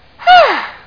sigh.mp3